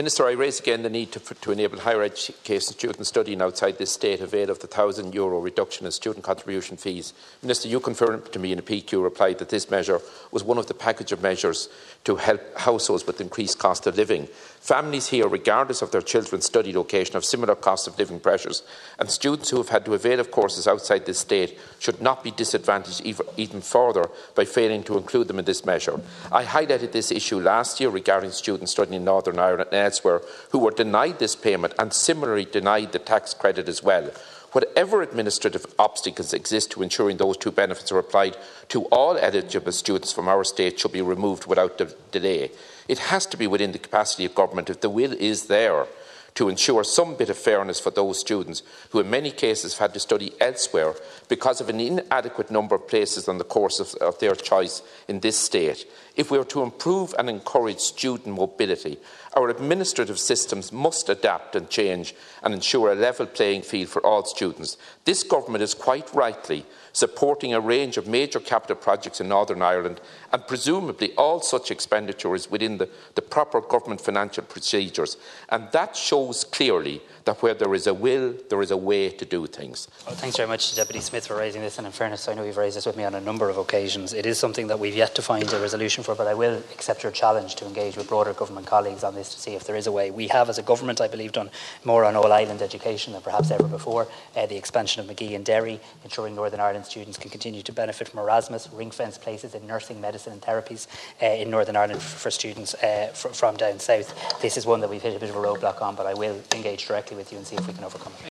The issue was raised in the Dail by Cavan Monaghan Deputy Brendan Smyth.